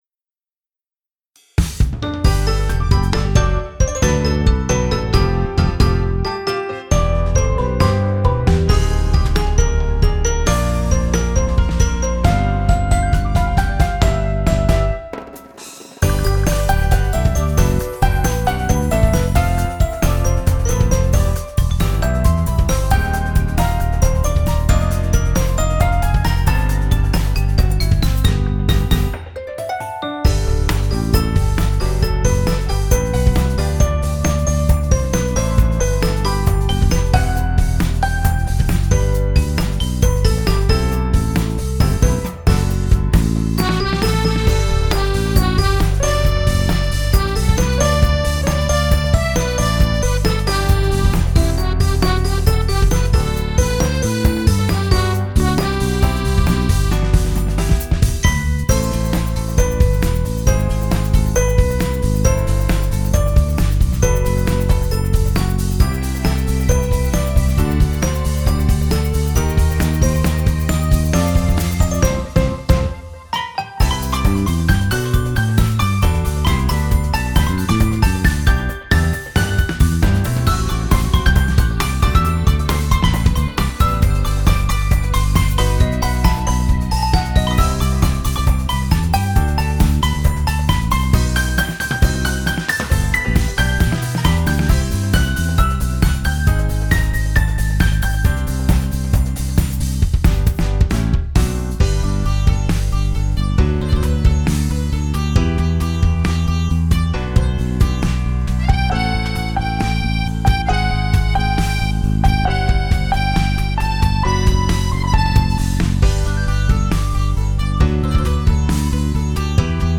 明るい曲が作りたくてできた曲。 爽やかだけど、少し切なさも感じさせるような、そんな曲。
BPM 120
優しい 切ない 風 明るい ウキウキ